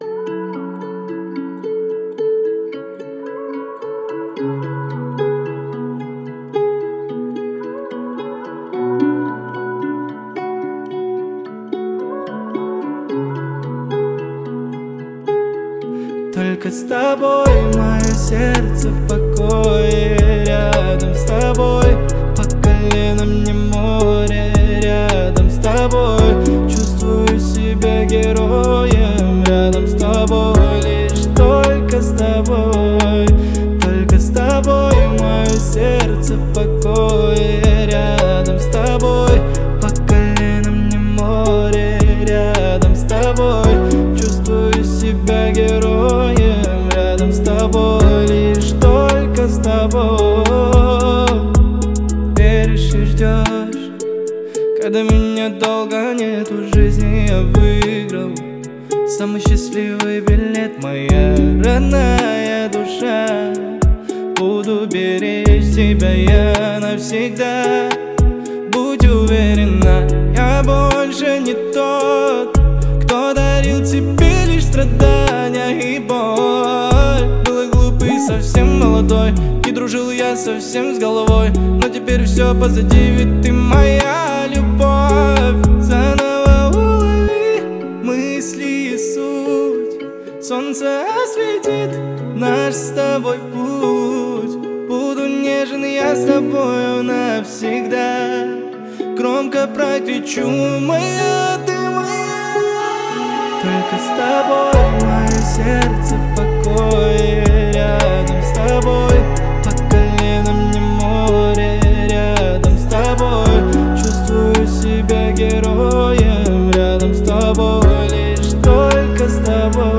Качество: 320 kbps, stereo
Поп музыка, Новинки